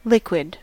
Ääntäminen
Synonyymit liquid phase Ääntäminen US Tuntematon aksentti: IPA : /ˈlɪkwɪd/ Lyhenteet liq.